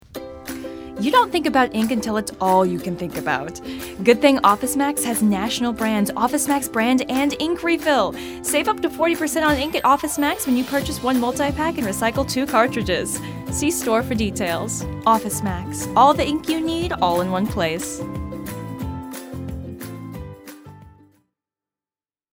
Commercial Clip